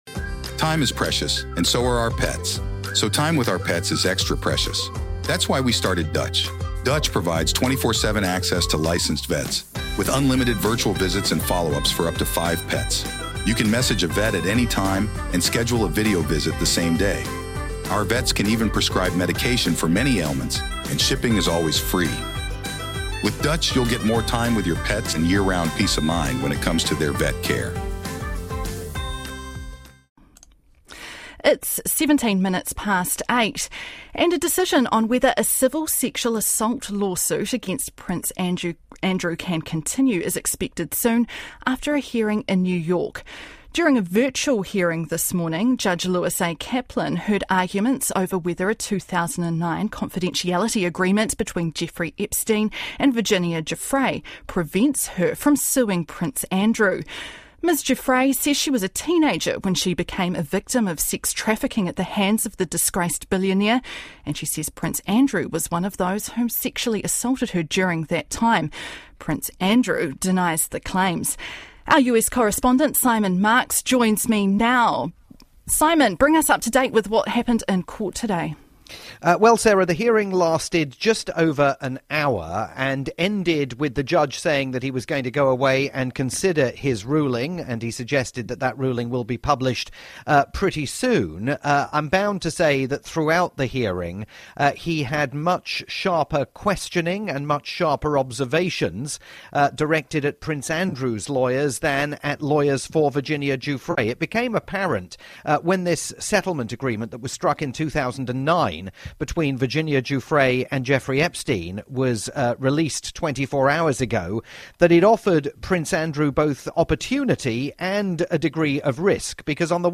live report on this morning's hearing in New York, as the Duke of York tries to secure the dismissal of the civil lawsuit against him.